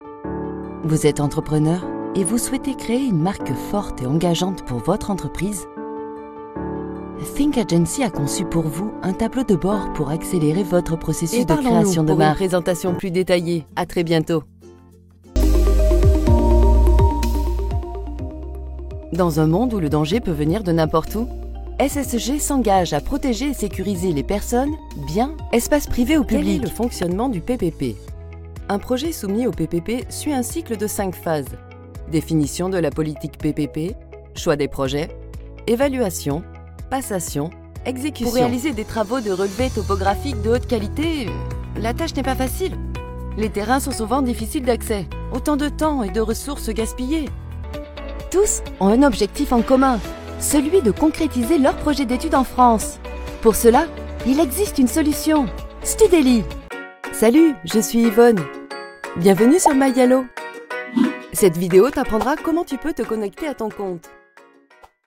Audioguide - I have a broadcast-quality studio: Neumann TLM 103 microphone, M-Audio Fast Track Ultra sound card, Vovox cables, acoustic cab.
Sprechprobe: eLearning (Muttersprache):